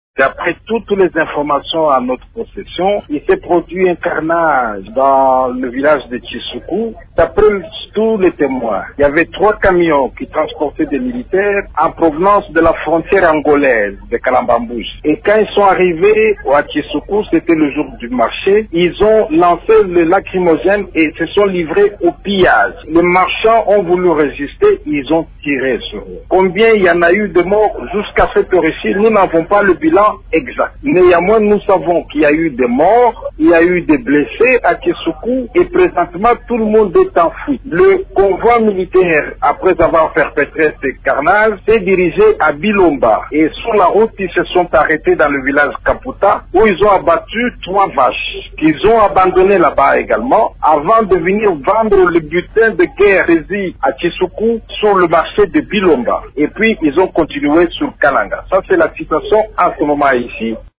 Joint au téléphone par Radio Okapi dimanche dans la soirée, Claudel André Lubaya, a relaté les faits: